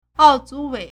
奥组委 (奧組委) ào zǔwēi